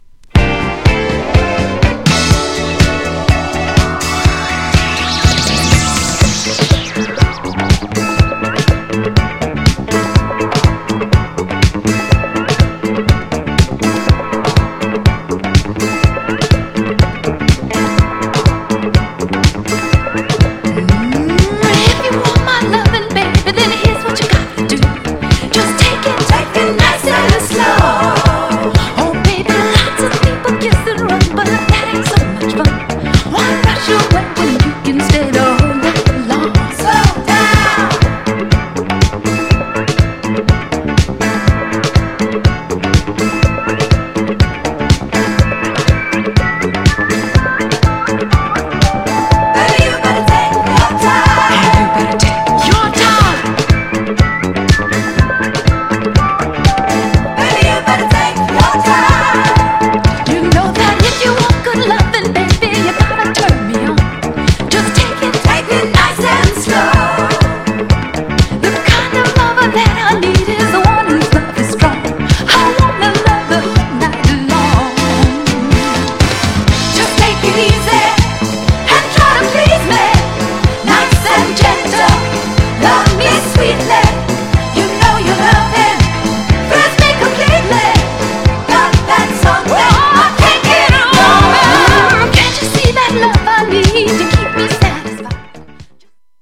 GENRE Dance Classic
BPM 126〜130BPM
アップリフティング # コズミック # ドラマティック # ハートウォーム # 妖艶